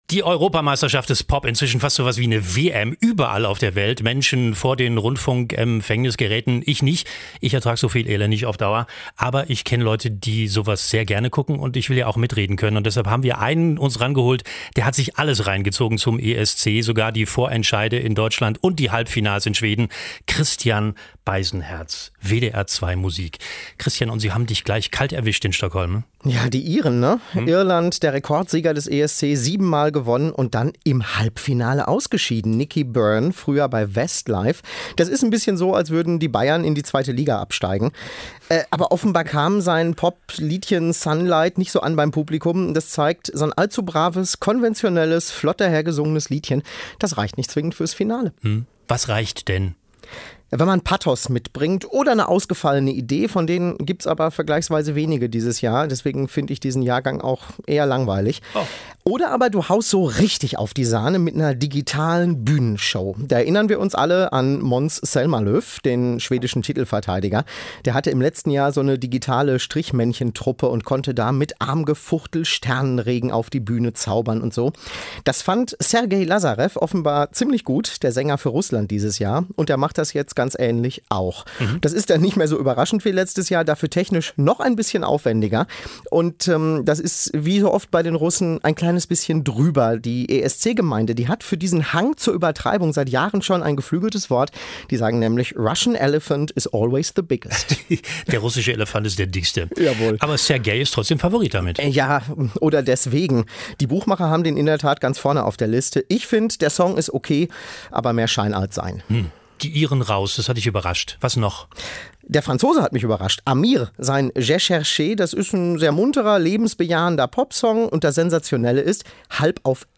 Das bringt der ESC 2016 – ein Radiotalk.